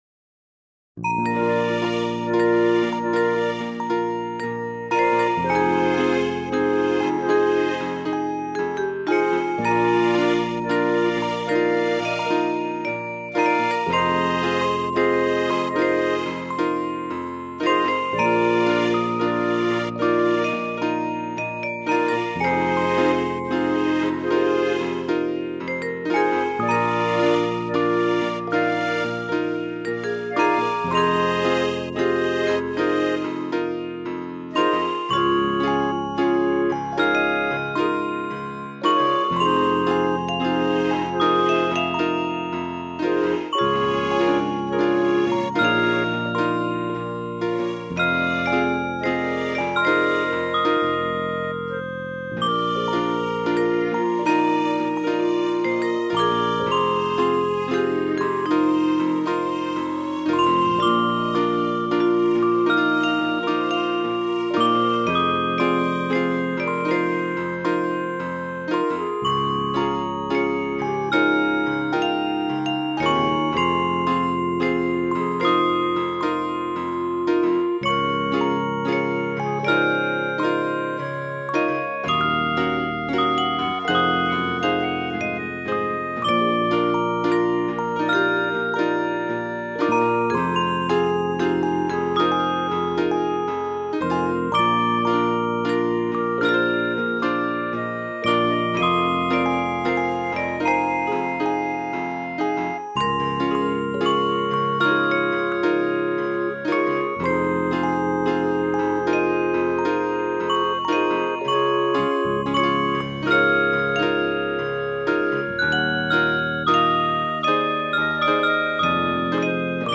Another BGM music, cherry tune type.